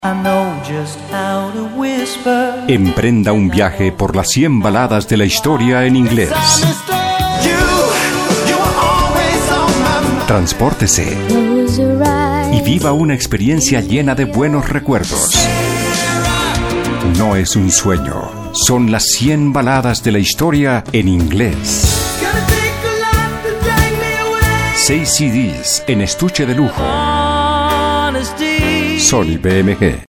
His elegant, multitalented and distinctive delivery is recognized as the very best in the industry.
spanischer Sprecher (Südamerika) voice-talent voice-over Colombian Native Locutor Neutro Colombiano
Sprechprobe: Sonstiges (Muttersprache):
spanish voice-talent (South America) voice-over Colombian Native Locutor Neutro Colombiano